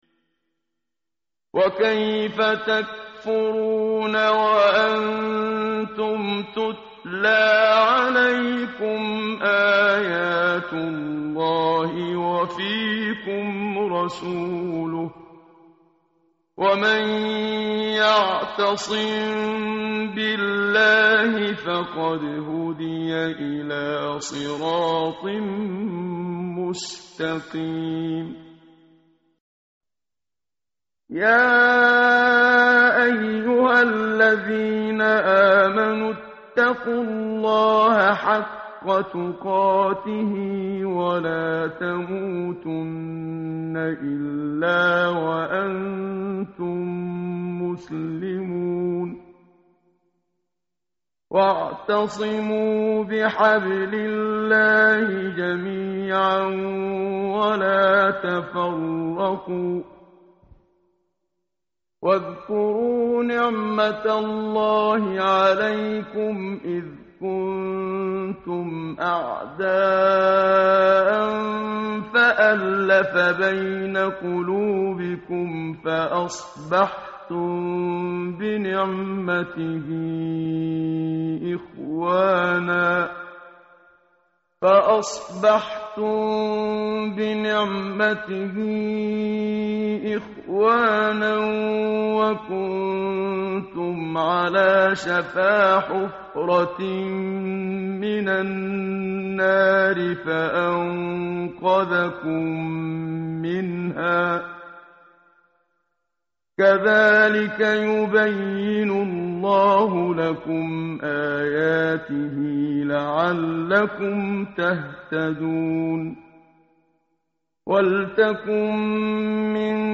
متن قرآن همراه باتلاوت قرآن و ترجمه
tartil_menshavi_page_063.mp3